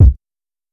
Kick (8).wav